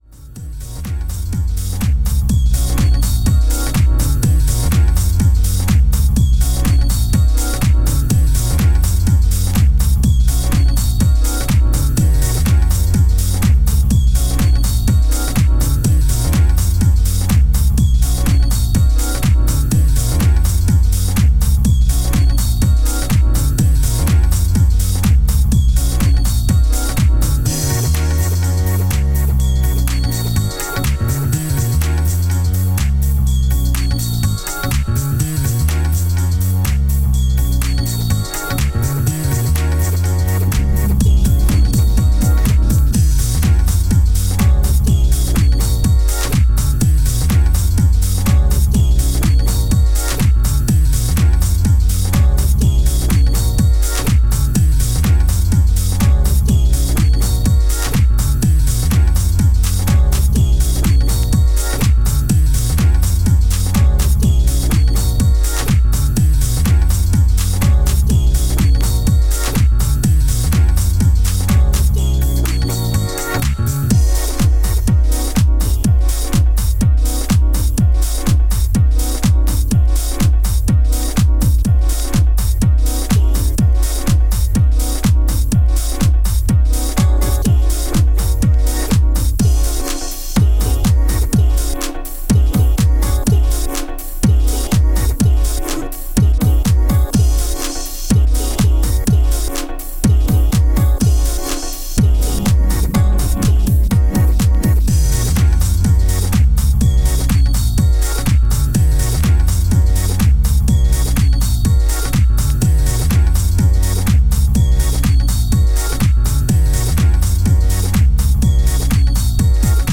90's NYマナーのバウンシーなディスコ・リコンストラクト作品です。